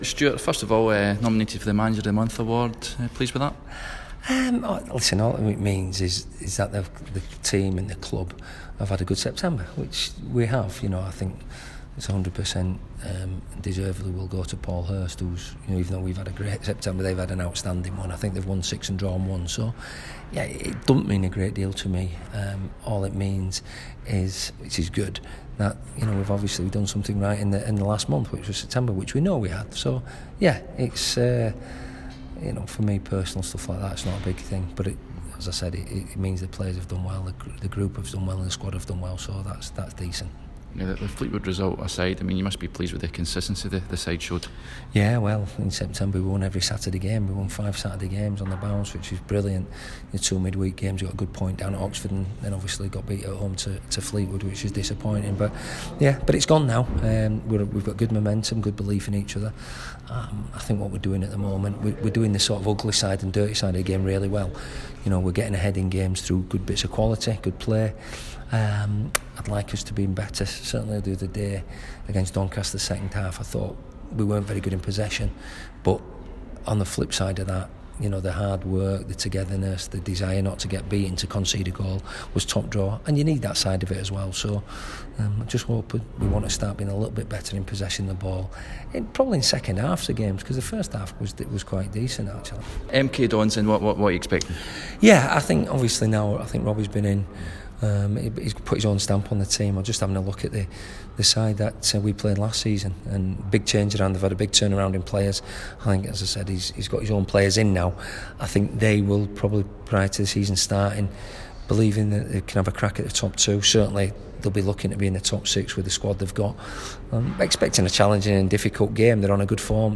Bradford City boss Stuart McCall speaks to Radio Yorkshire ahead of his side taking on MK Dons in Milton Keynes on Saturday.